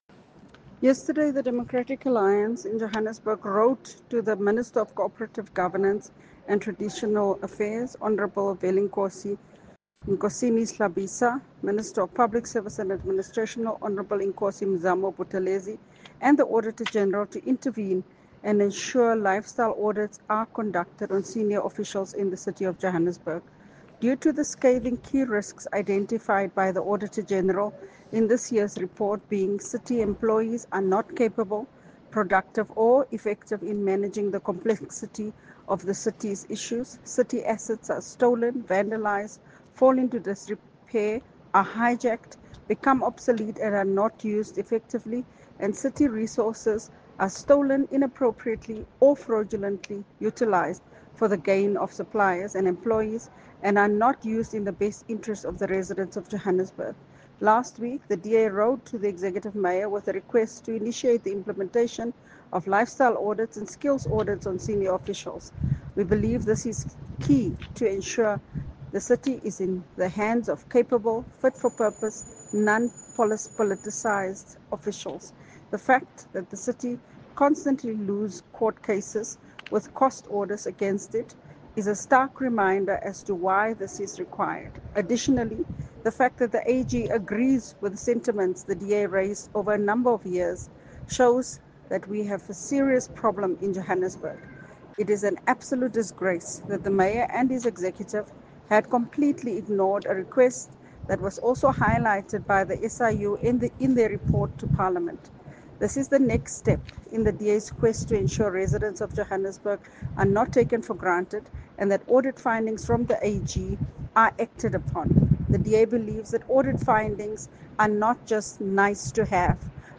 Note to Editors: Please find an English soundbite by Cllr Belinda Kayser-Echeozonjoku